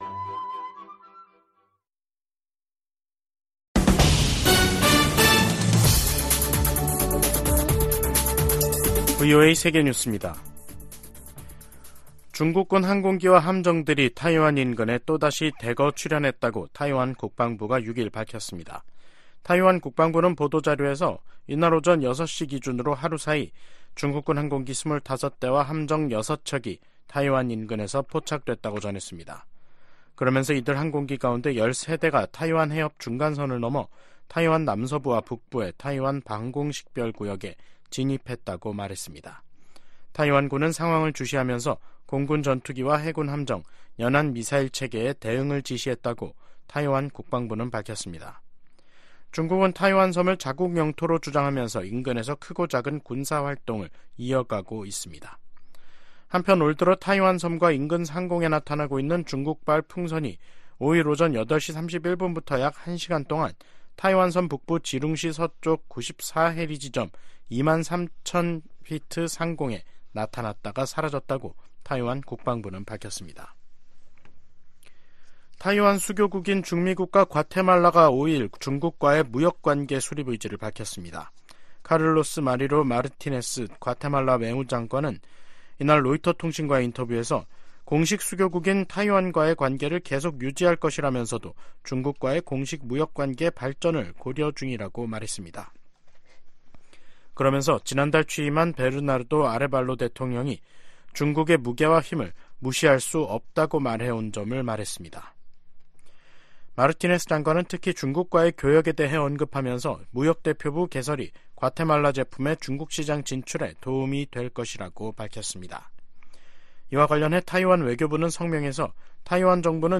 VOA 한국어 간판 뉴스 프로그램 '뉴스 투데이', 2024년 2월 6일 3부 방송입니다.